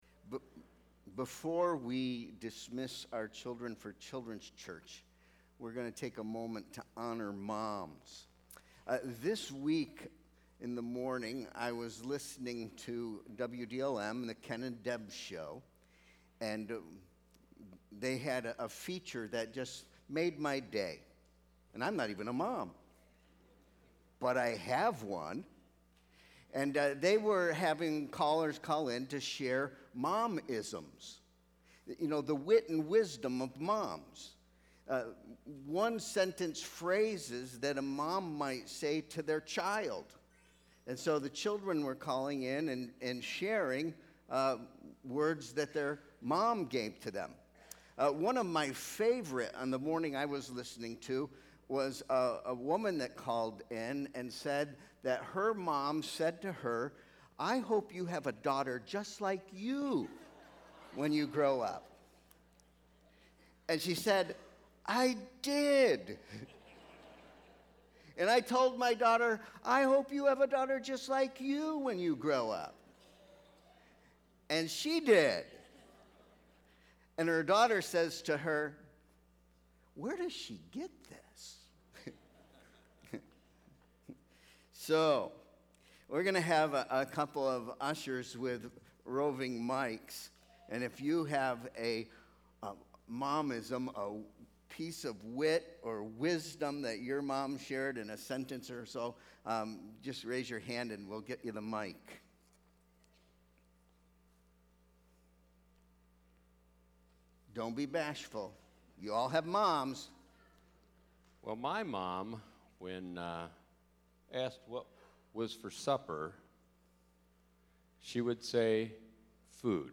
Sermon Proposition: You can know Christ as Savior, Shepherd, and King.